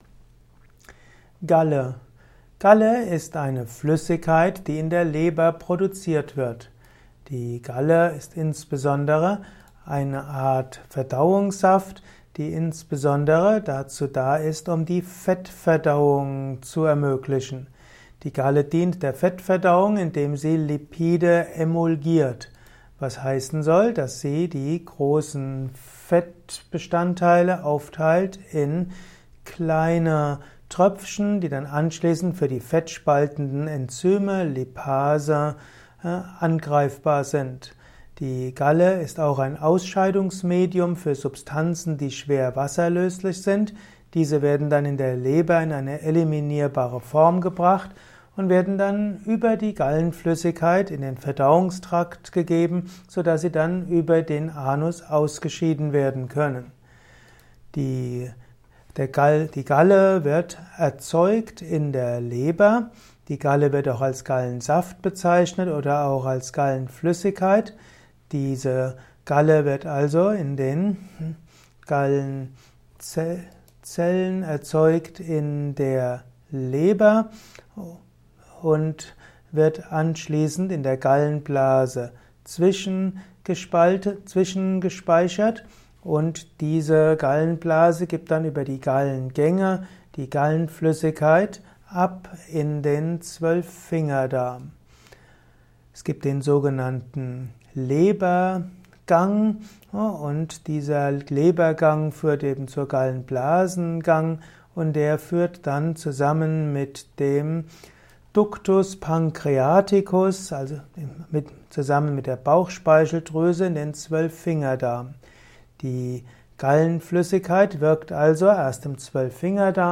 Ein Kurzvortrag über die Galle